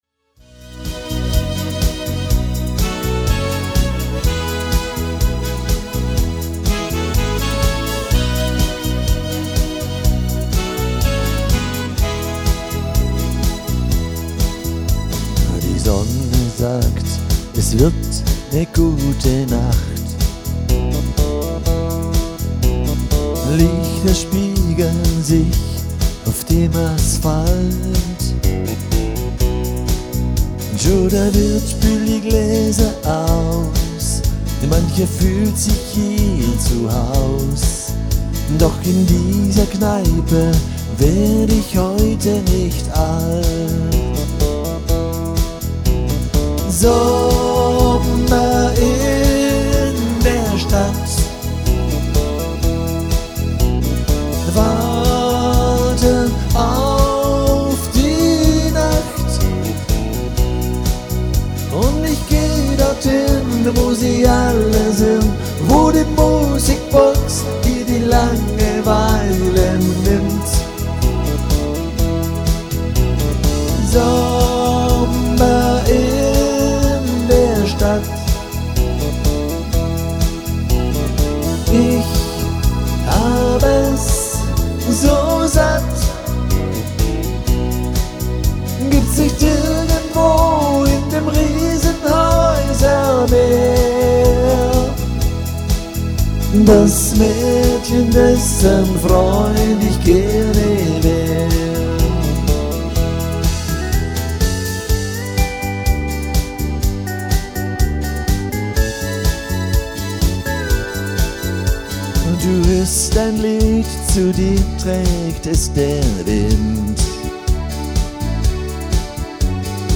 • Alleinunterhalter